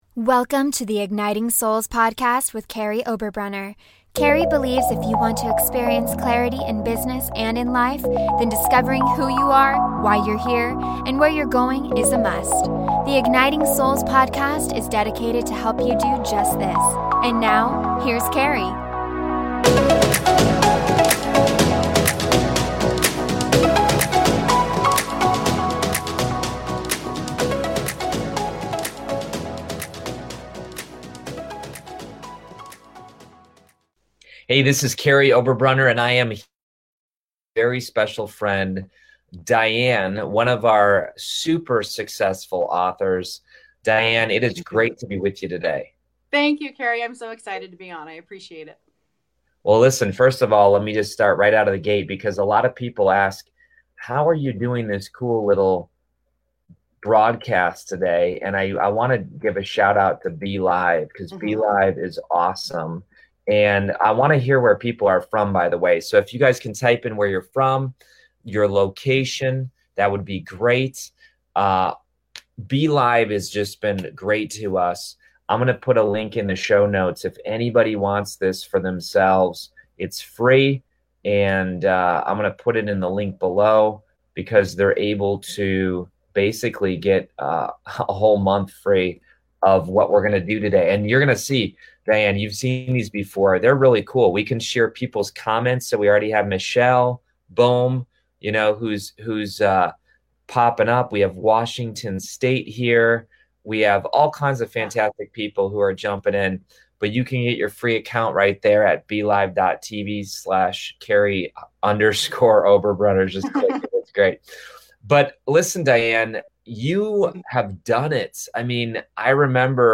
In this interview, she shares 3 secrets for launching your book, including the strategy of a launch party, how to use media to promote your book and how to borrow other people's platforms.